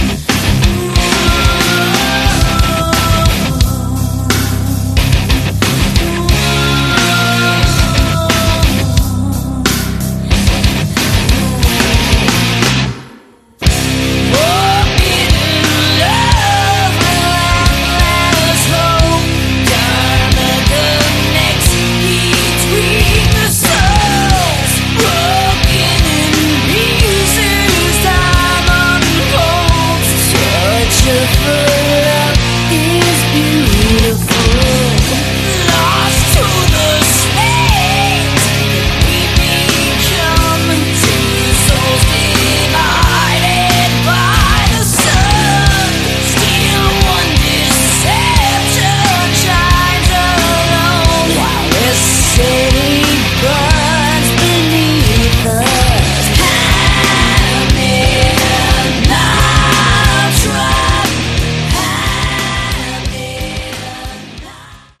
Category: Melodic Metal
lead and backing vocals
guitars, backing vocals
bass
drums, backing vocals